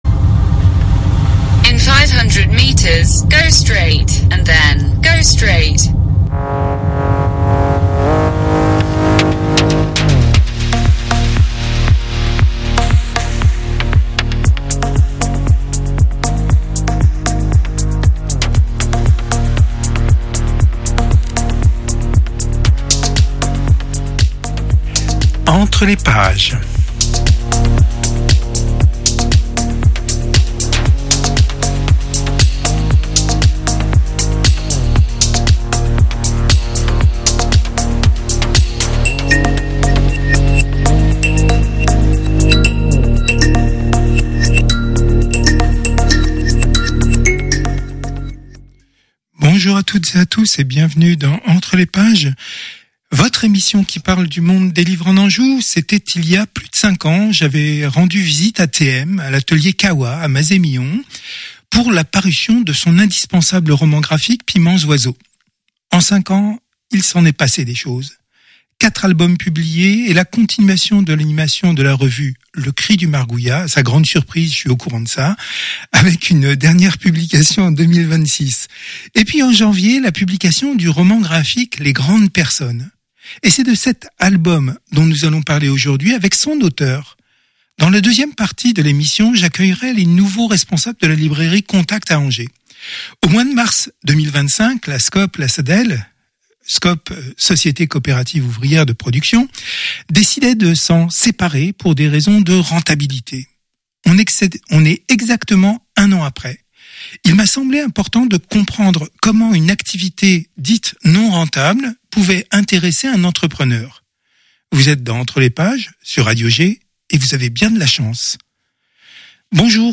ENTRE LES PAGES, c’est une heure consacrée à l’univers des livres en Anjou. Interviews, reportages, enquêtes, sont au menu.